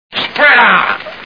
Three Stooges Movie Sound Bites